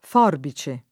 f0rbi©e] s. f. — in senso proprio, usato di regola nel pl. ‑ci — sim. i cogn. Forbice, Forbici — preval. in antico la var. forfice [f0rfi©e], diretta continuaz. del lat. forfex -icis (alterata poi forse per accostam. a forbire) — ant. oscillaz. tra forf- e forb- anche nel dim.: con un paio di forficette [kon um p#Lo di forfi©%tte] (Boccaccio); con le forbicine o col rasoio [kon le forbi©&ne o kkol raS1Lo] (Sacchetti) — forficetta e forbicina, inoltre, sinonimi di forfecchia (insetto del genere forficola)